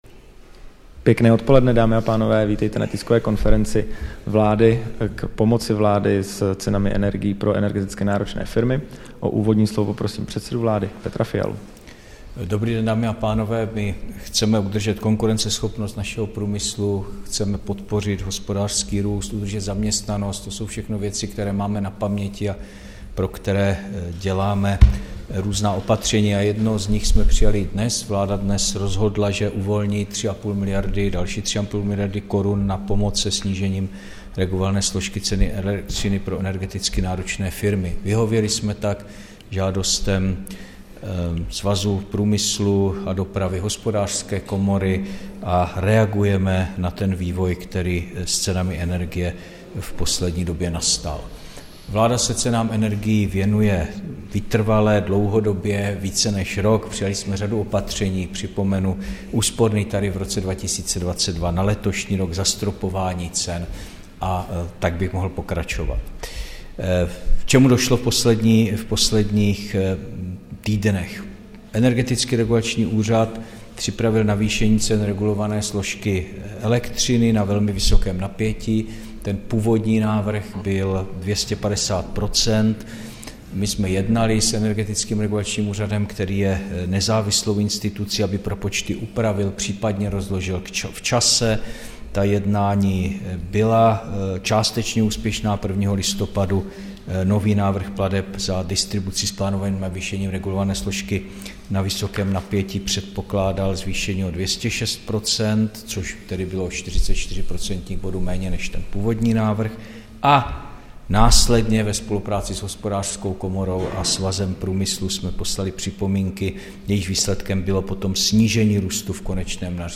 Tisková konference k pomoci státu energeticky náročným firmám s cenami energií, 13. prosince 2023